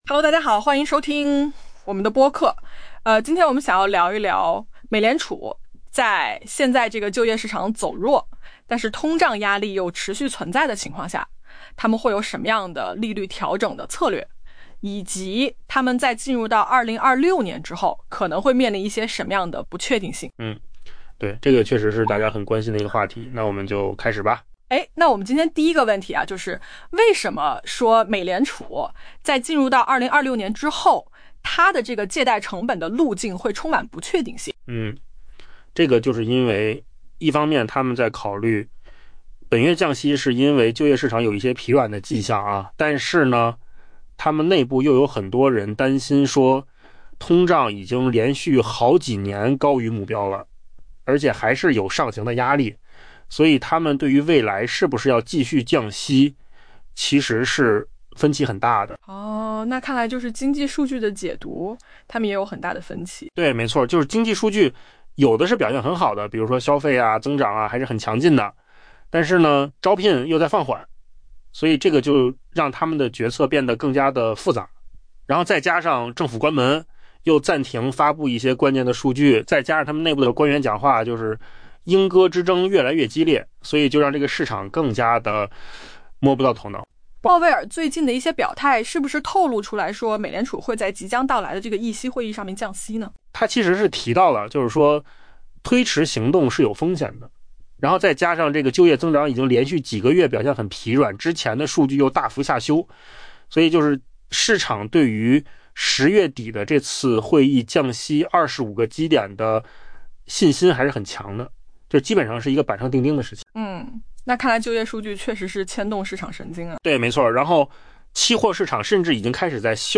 AI 播客：换个方式听新闻 下载 mp3 音频由扣子空间生成 美联储准备在本月再次降息，因为眼下，一个正在走弱的就业市场比通胀的担忧更占上风，但这种平衡可能不会维持太久。